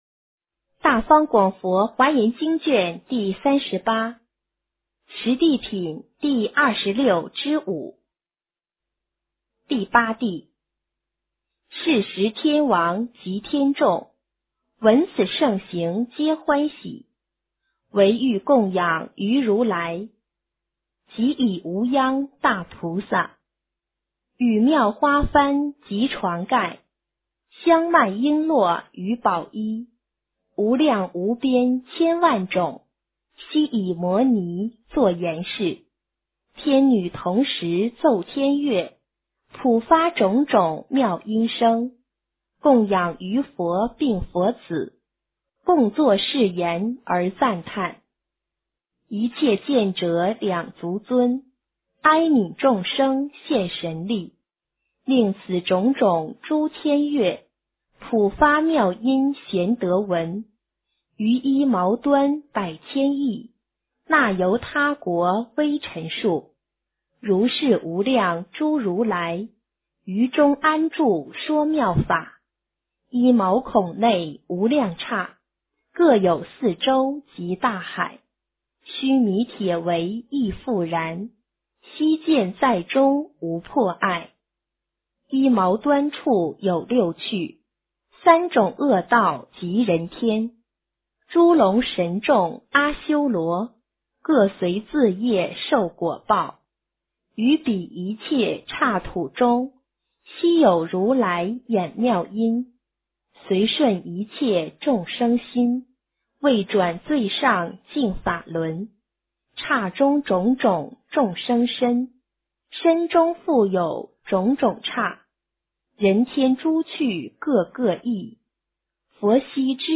华严经38 - 诵经 - 云佛论坛